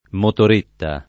vai all'elenco alfabetico delle voci ingrandisci il carattere 100% rimpicciolisci il carattere stampa invia tramite posta elettronica codividi su Facebook motoretta [ motor % tta ] s. f. — cfr. motor‑scooter ; scooter